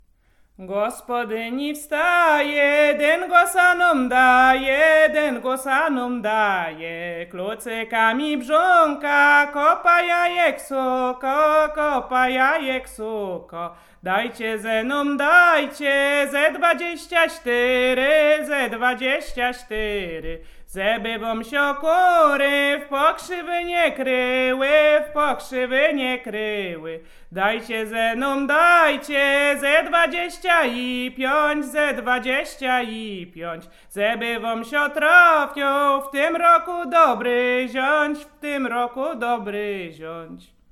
Wielkanocna
wielkanoc wiosenne wiosna dyngus dyngusowe kolędowanie wiosenne